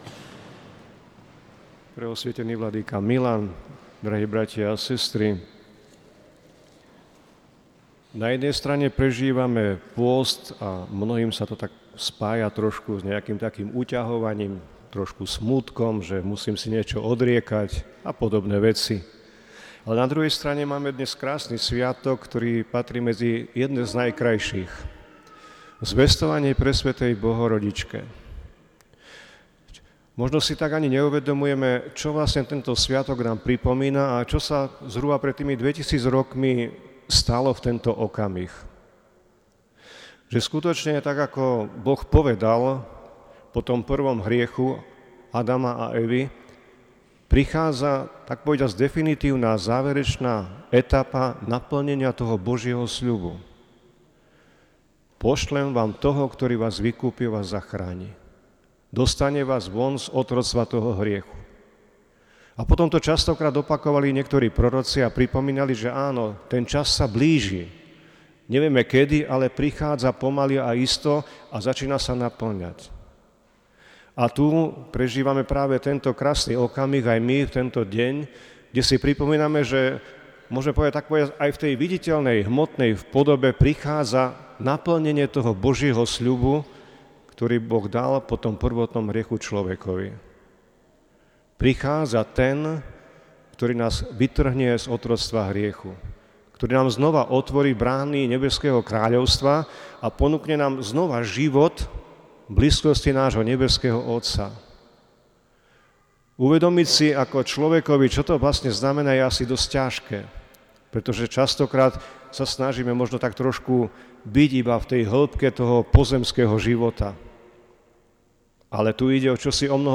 Marcová spomienka bl. Metoda pripadla na sviatok Zvestovania Bohorodičke.